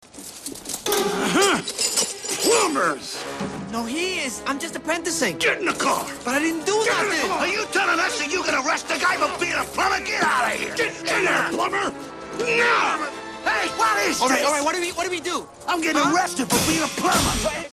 brooklynaccenttotheextreeeeme.mp3